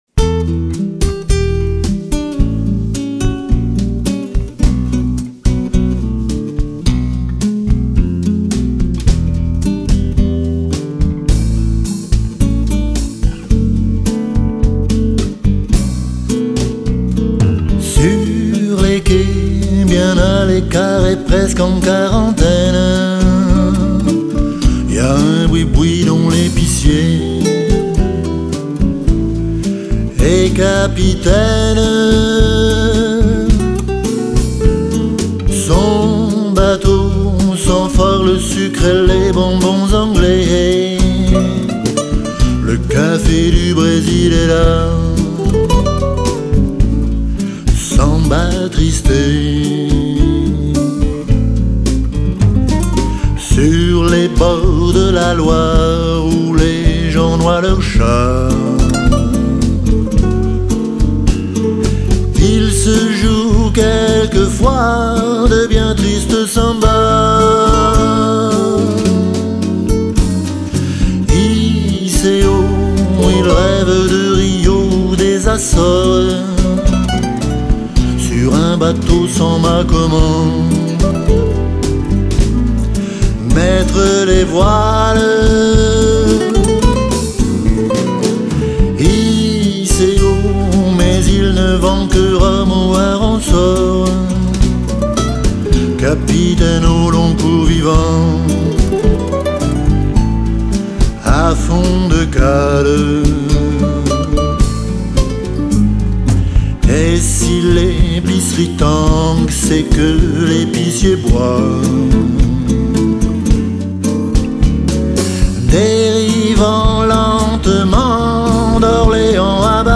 une ambiance plus mélancolique, plus blues, plus bossa